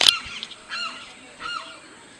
Limpkin (Aramus guarauna)
Province / Department: Tucumán
Location or protected area: Trancas
Condition: Wild
Certainty: Photographed, Recorded vocal